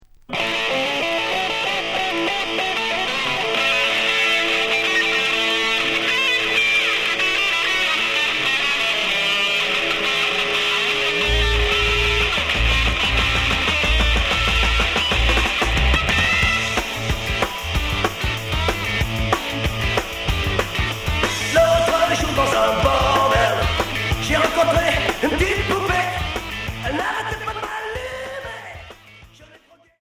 Punk hard Unique 45t retour à l'accueil